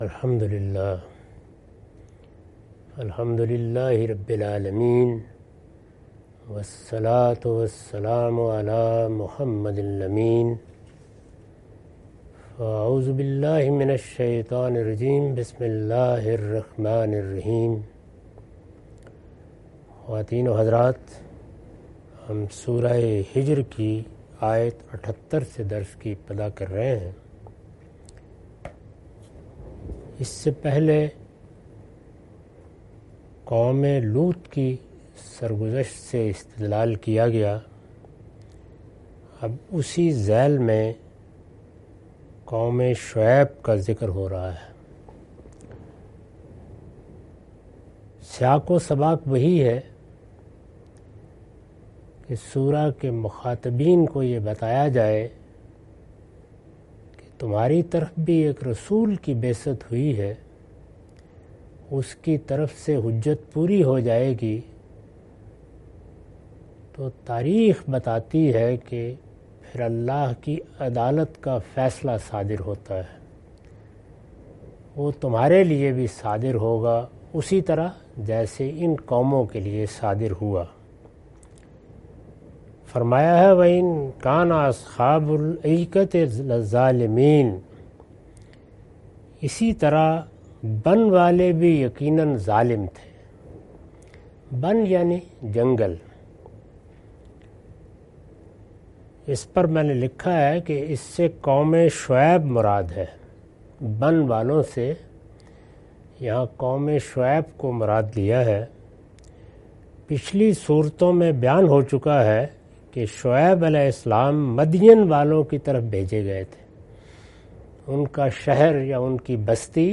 Surah Al-Hijr- A lecture of Tafseer-ul-Quran – Al-Bayan by Javed Ahmad Ghamidi. Commentary and explanation of verses 78-85.